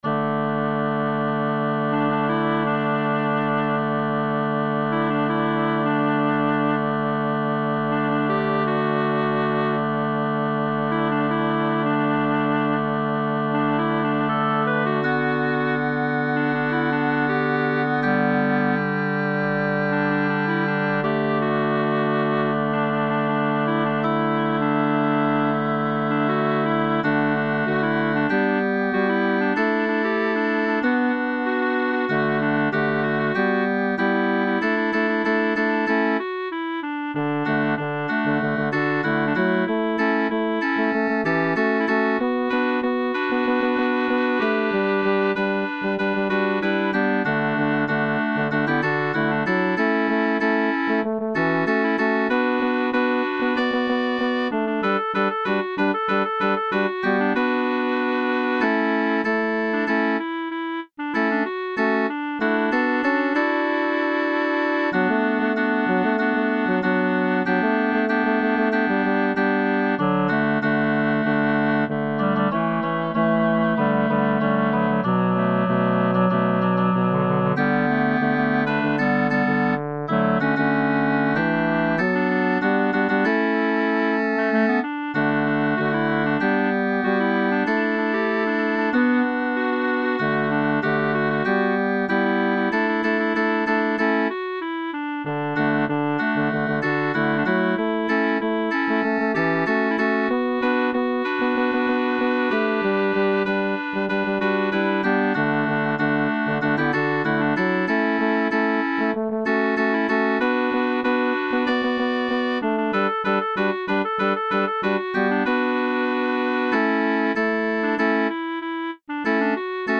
Upper Voices Performance